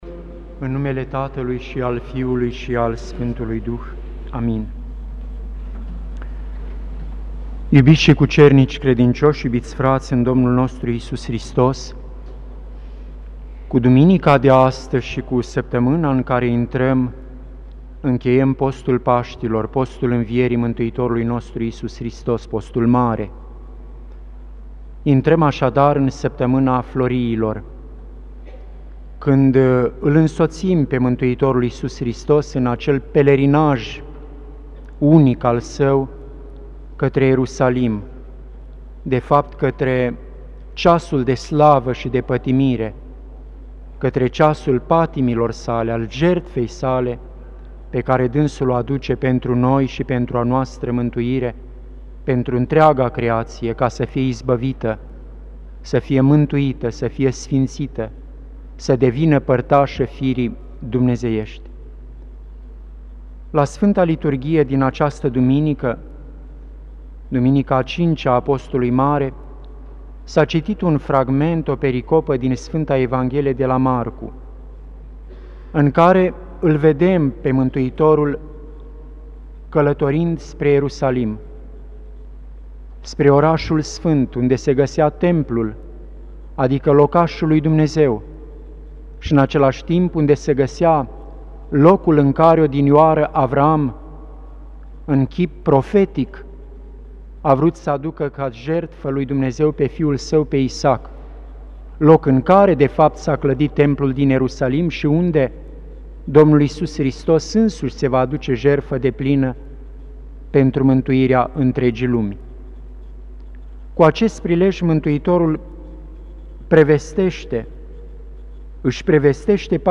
Predică la Duminica a 5-a din Post
Cuvinte de învățătură Predică la Duminica a 5-a din Post